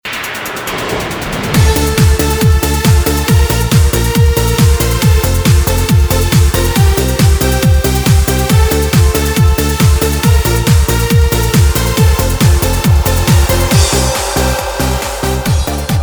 Hab hier 'nen Demo, diesmal mit Cubase AI5 gemacht.
Anhänge bumsbirne-schlager5.mp3 626 KB · Aufrufe: 228